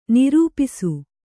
♪ nirūpisu